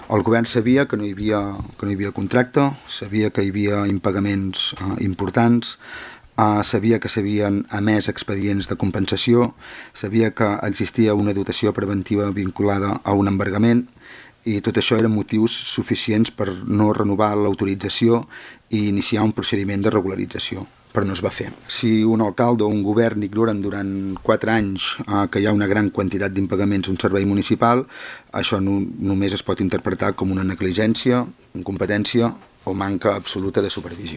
Debat tens en l’última sessió plenària per les posicions enfrontades entre govern i oposició sobre la gestió del bar El Paso, que posarà punt final a la seva activitat amb el canvi d’any.